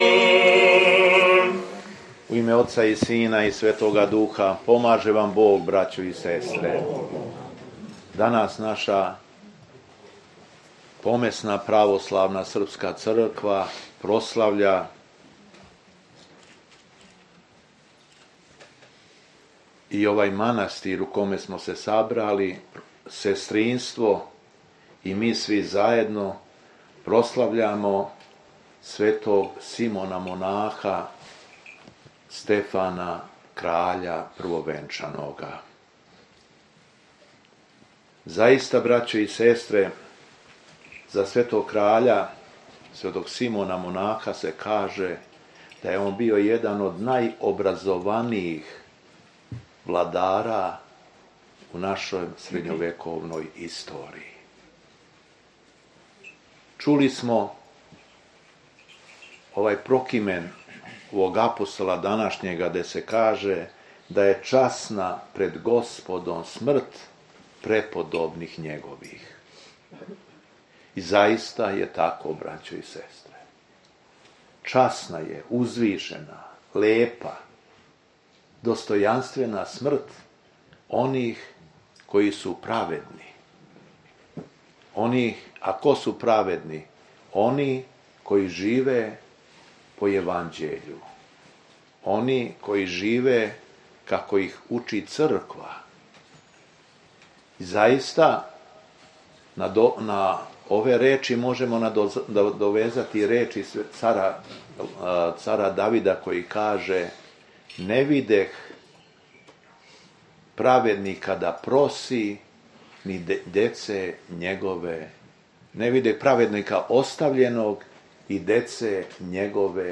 У понедељак, 7. октобра 2024. године, на празник светога Симона монаха и свете првомученице Текле, Његово Високопреосвештенство Митрополит шумадијски Г. Јован служио је Свету Архијерејску Литургију у манастиру Прерадовац поводом манастирске славе.
Беседа Његовог Високопреосвештенства Митрополита шумадијског г. Јована
Митрополит се верном народу обратио надахнутом беседом: